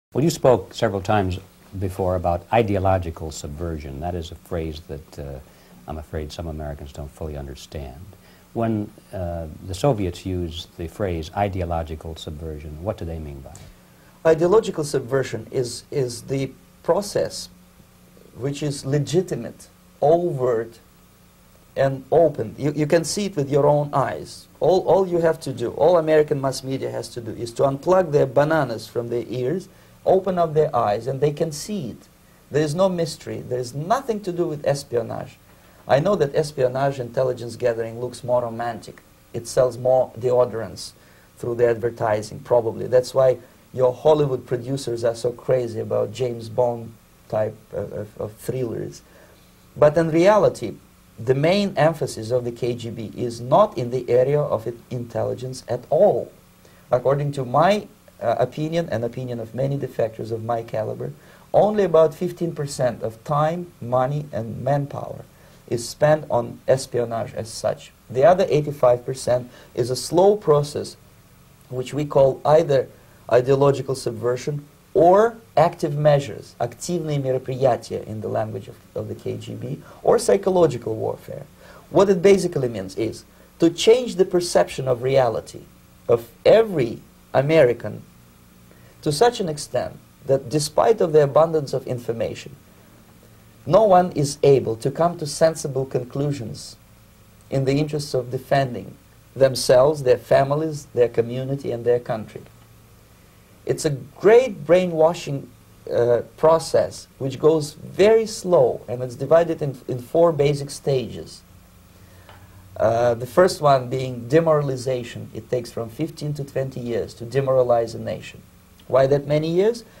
KGB Defector Yuri Bezmenov Interview 1985